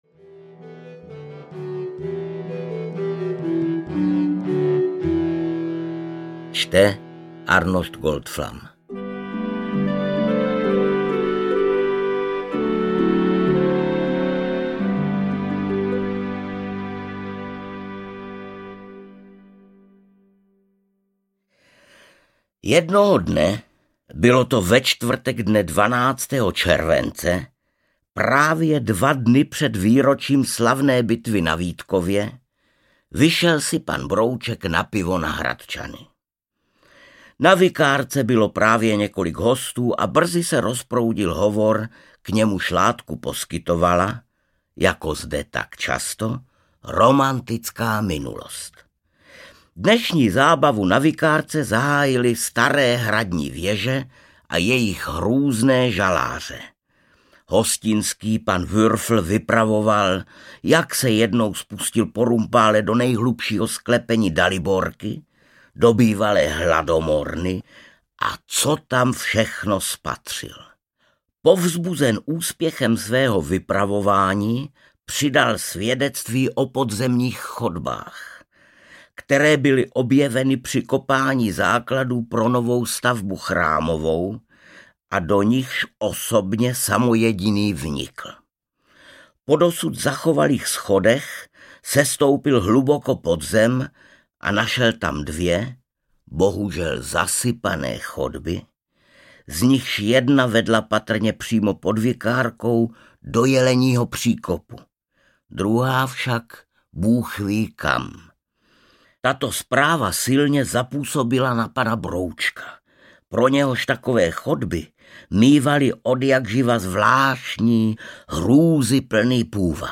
Epochální výlet pana Broučka do XV. století audiokniha
Ukázka z knihy
• InterpretArnošt Goldflam